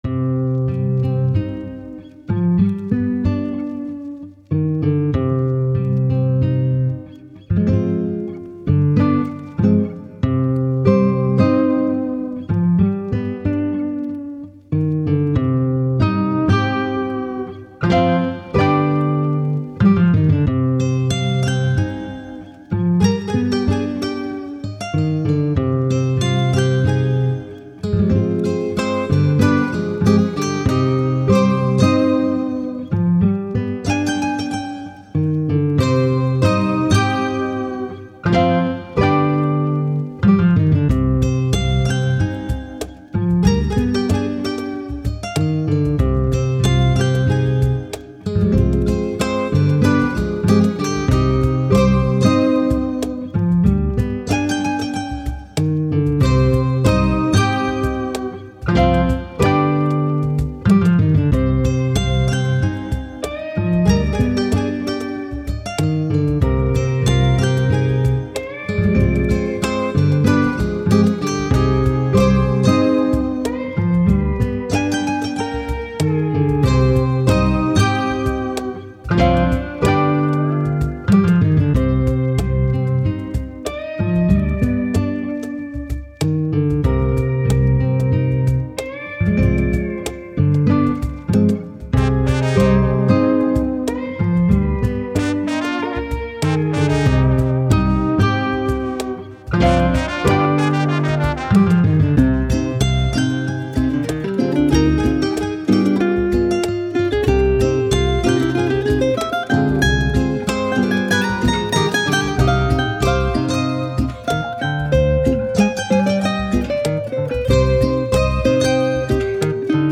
Spanish, Classical, Thoughtful, Landscapes